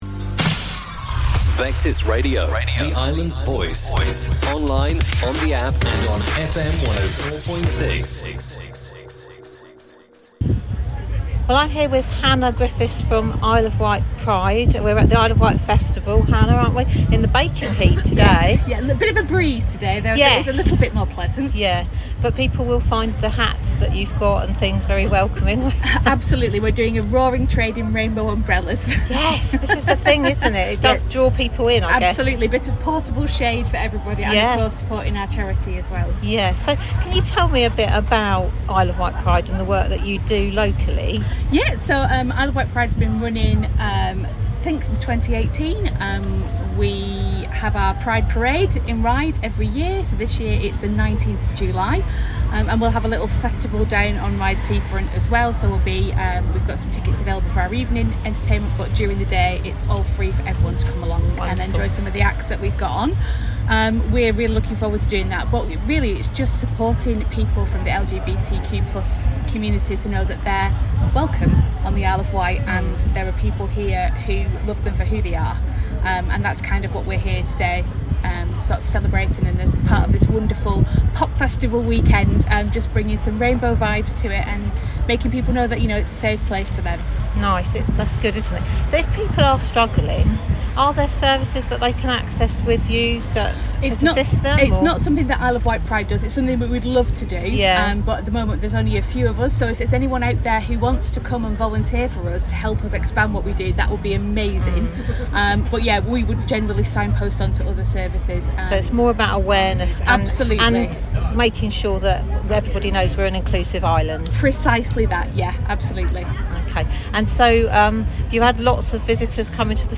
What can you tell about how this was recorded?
Isle of Wight Festival 2025